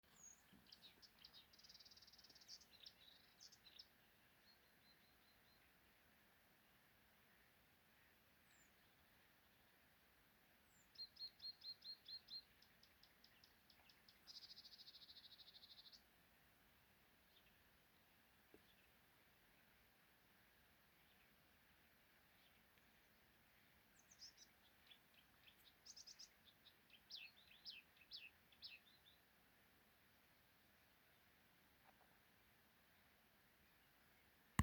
болотная камышевка, Acrocephalus palustris
СтатусПоёт